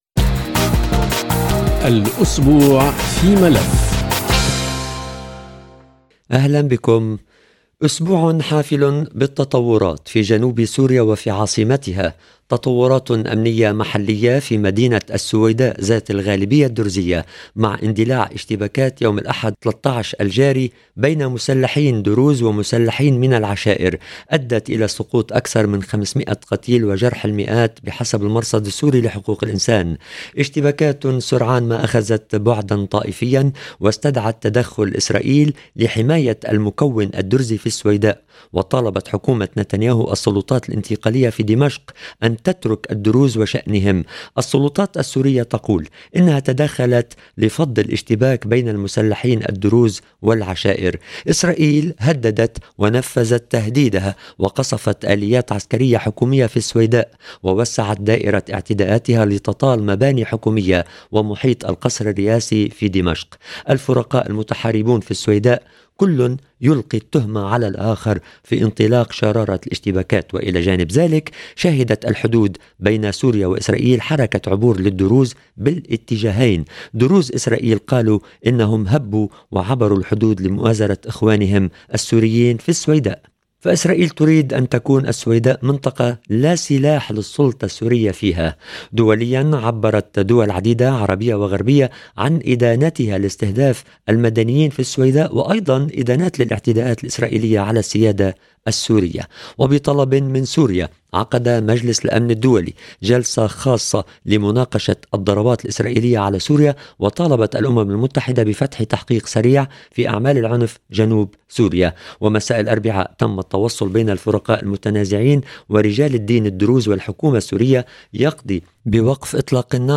برنامج الأسبوع في ملف يعود إلى أحداث مدينة السويداء جنوب سوريا منذ الأحد الماضي مع أبرز مقتطفات لمداخلات ضيوف برنامج ملف اليوم تناولت طبيعة الأحداث وأبعادها محلياً وإقليمياً.